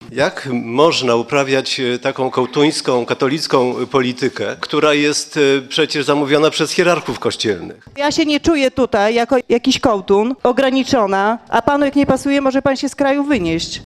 Kłótnia na posiedzeniu sejmowej komisji zdrowia o tabletkę 'dzień po’.